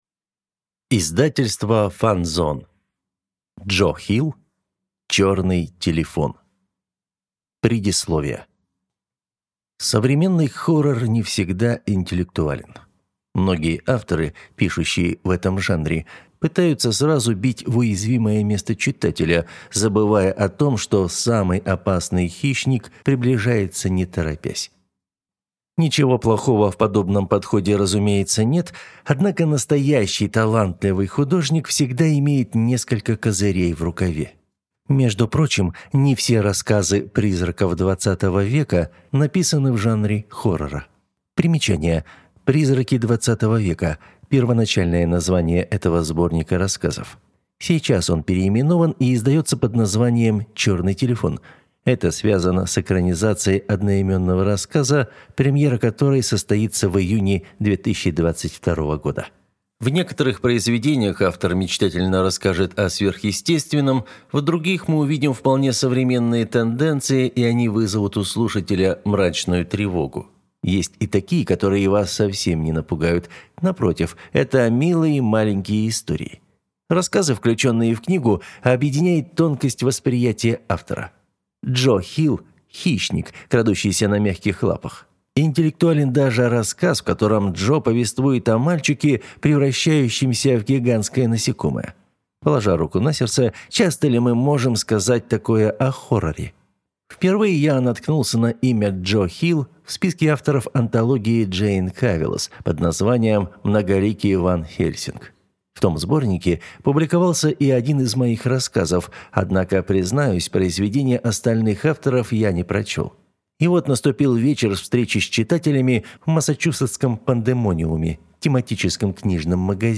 Аудиокнига Черный телефон | Библиотека аудиокниг
Прослушать и бесплатно скачать фрагмент аудиокниги